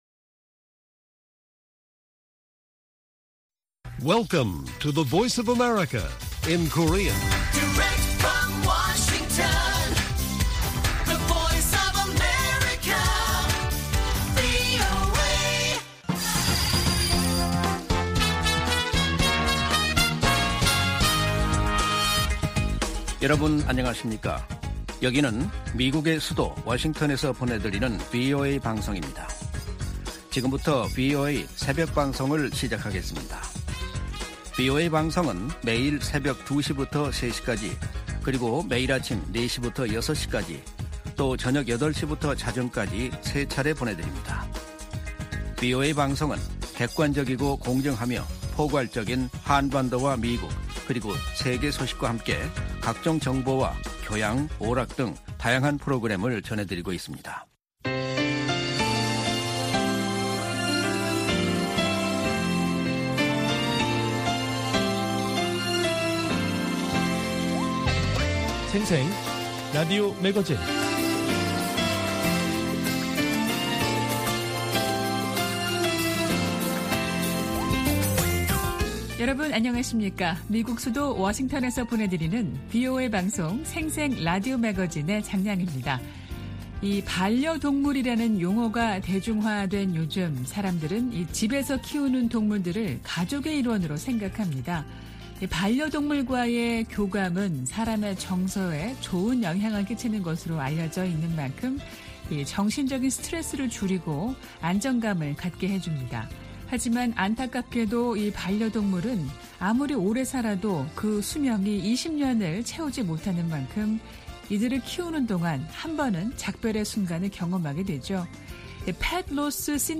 VOA 한국어 방송의 일요일 새벽 방송입니다. 한반도 시간 오전 2:00 부터 3:00 까지 방송됩니다.